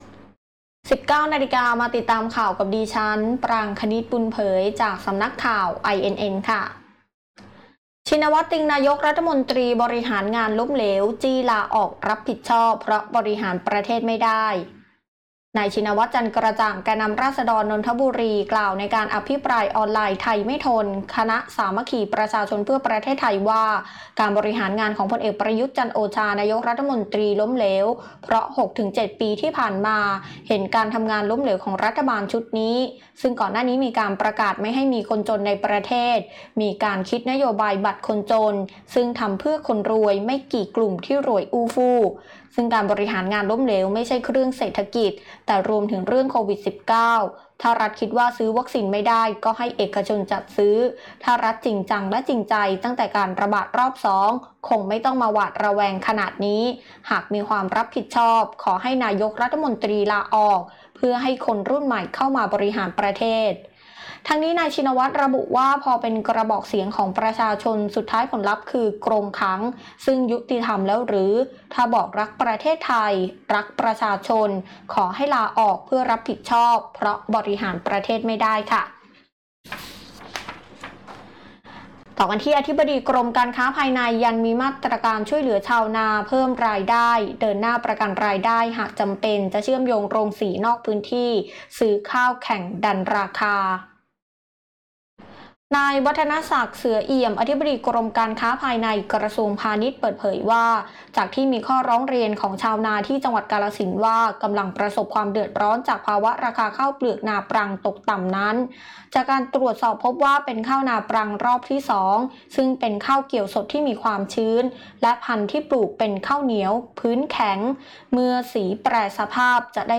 คลิปข่าวต้นชั่วโมง